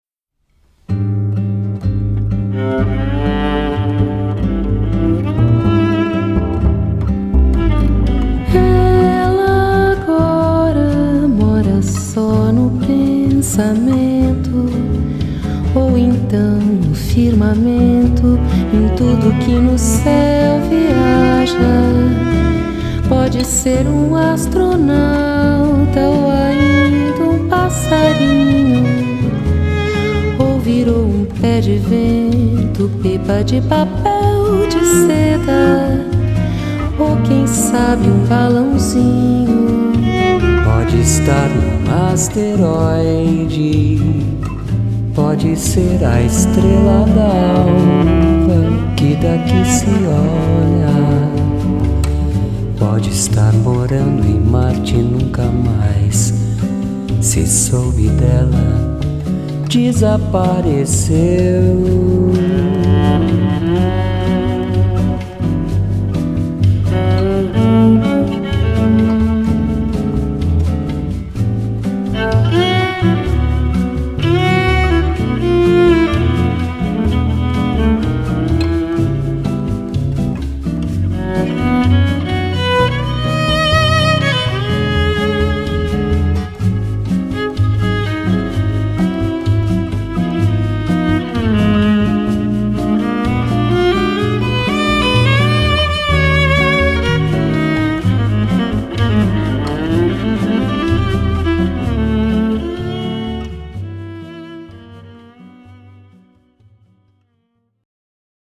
Brazilian Jazz band